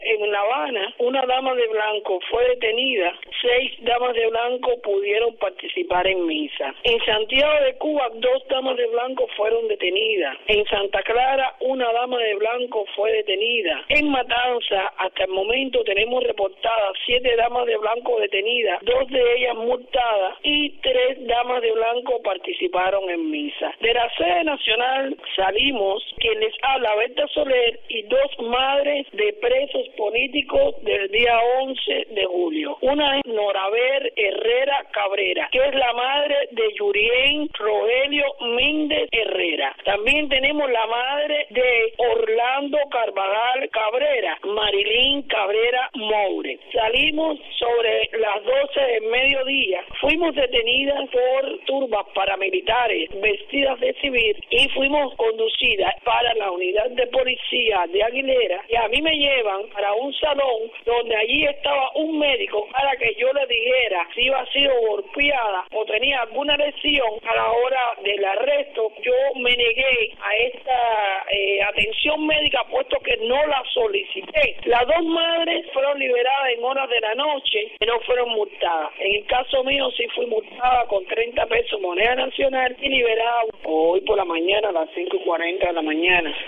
Declaraciones de Berta Soler a Radio Martí